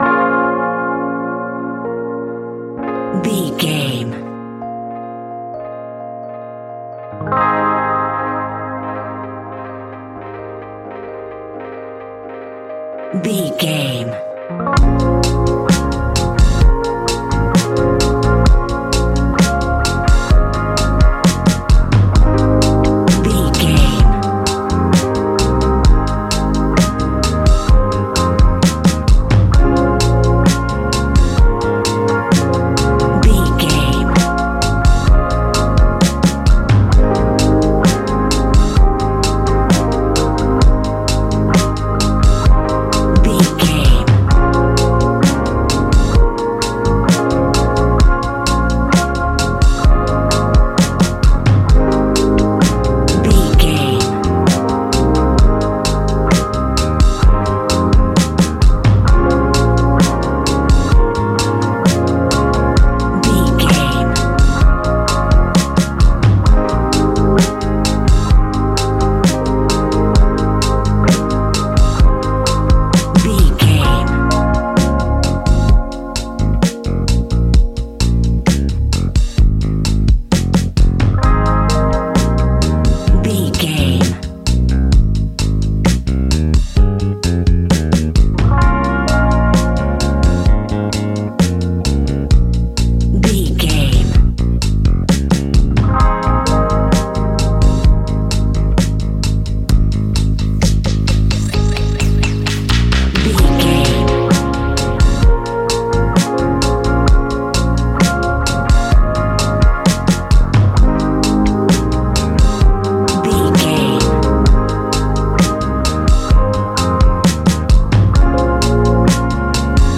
Ionian/Major
G♯
laid back
Lounge
new age
chilled electronica
ambient